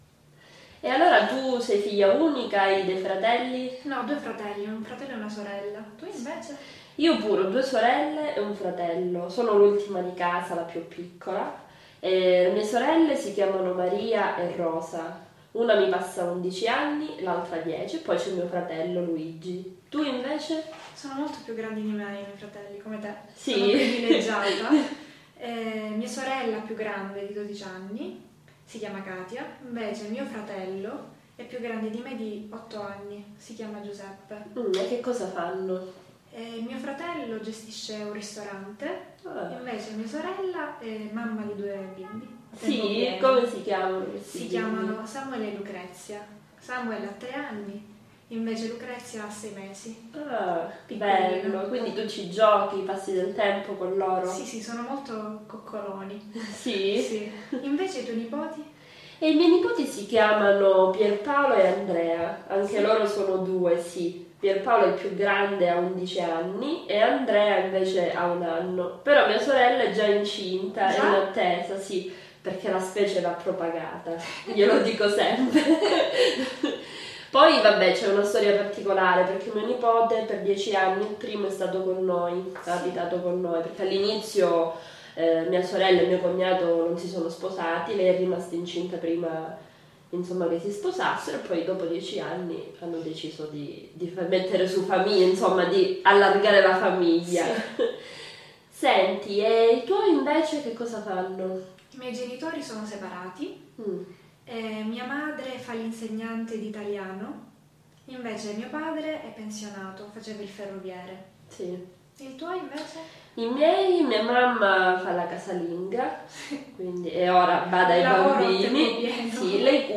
Autentické rozhovory zahŕňajú rôzne bežné a každodenné témy, aby čo najviac pomohli osvojiť si slovné spojenie a konverzačné obraty, ktoré rodení Taliani používajú v každodennom styku.
Ukázka z knihy
Audiokniha GLI ITALIANI je ojedinelou a unikátnou zbierkou autentických rozhovorov rodených Talianov.